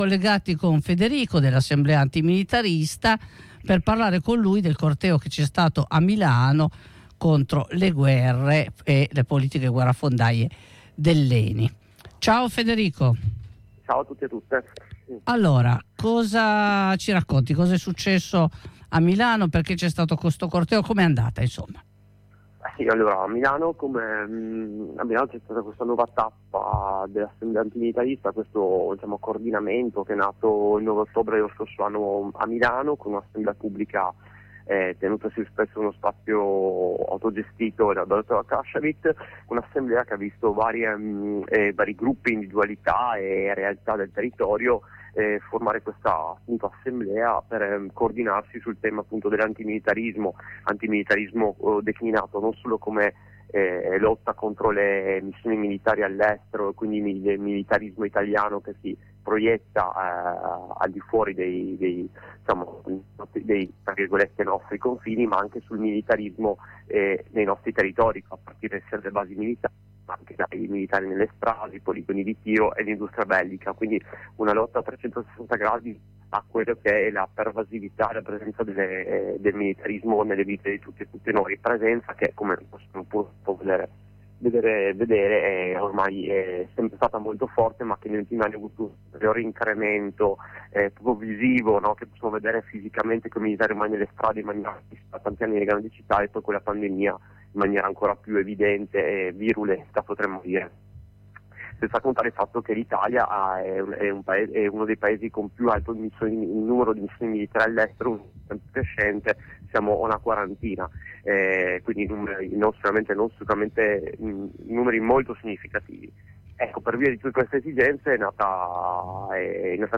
Ascolta la diretta: https